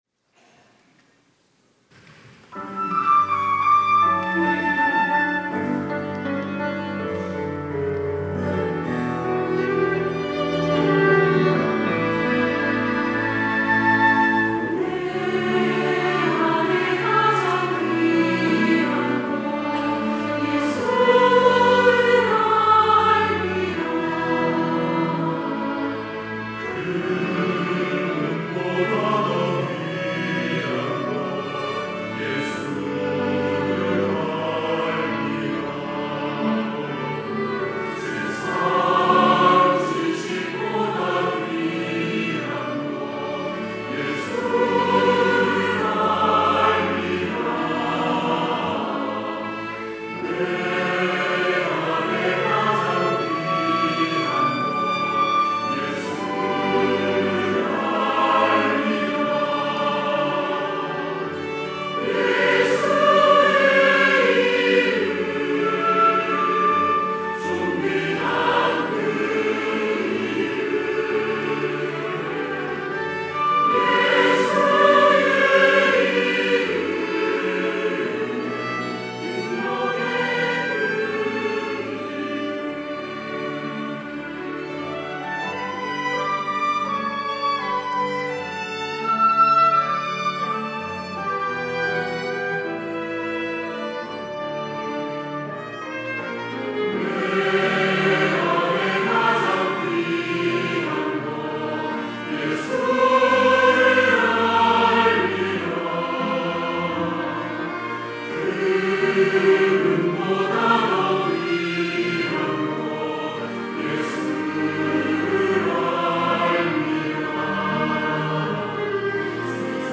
할렐루야(주일2부) - 내 안에 가장 귀한 것
찬양대